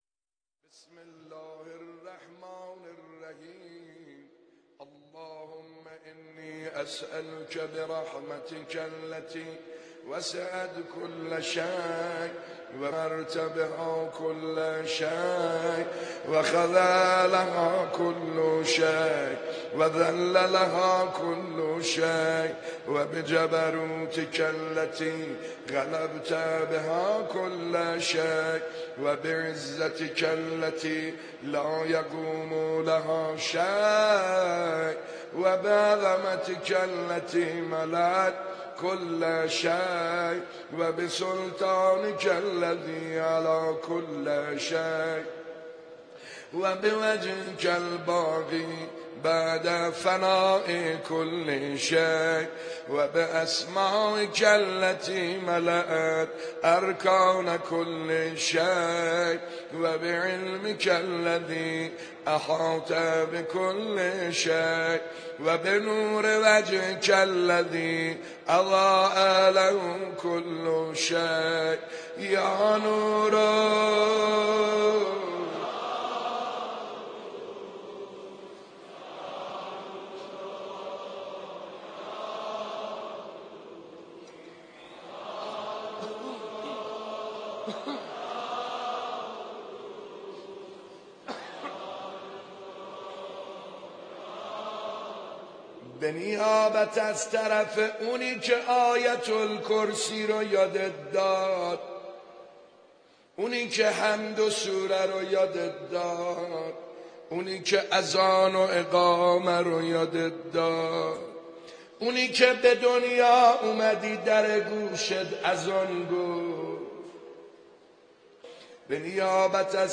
دانلود فیلم و صوت دعای پرفیض کمیل با صدای حاج سعید حدادیان به همراه متن و ترجمه
در این بخش، دعای پرفیض کمیل را با نوای دلنشین حاج سعید حدادیان به مدت 39 دقیقه با اهل دعا و مناجات به اشتراک می گذاریم.